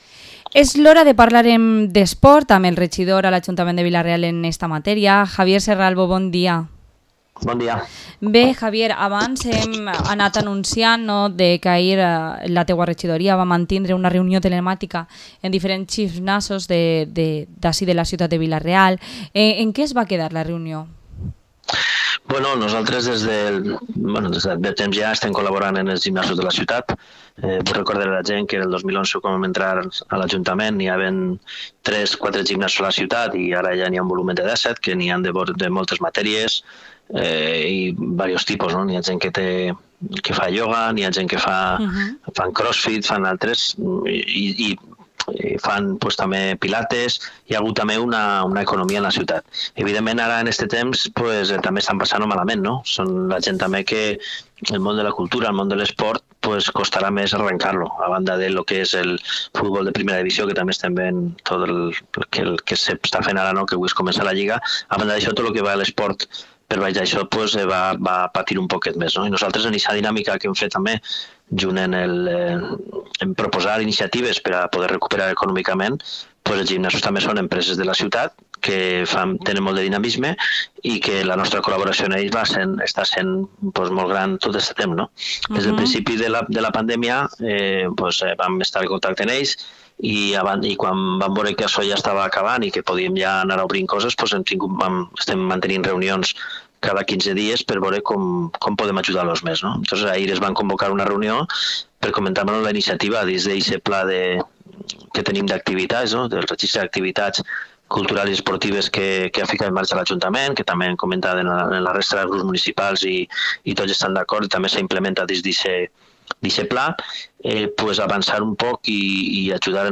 Entrevista al concejal de Deportes de Vila-real, Javier Serralvo